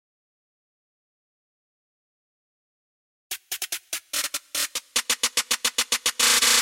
标签： 145 bpm Trap Loops Percussion Loops 1.11 MB wav Key : Unknown
声道立体声